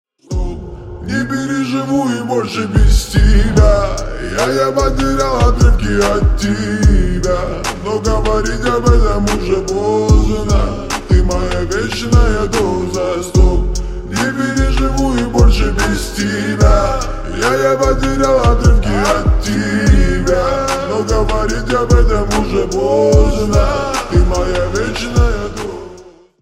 Рингтоны Ремиксы » # Рэп Хип-Хоп